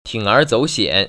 铤而走险[tǐng ér zǒu xiǎn]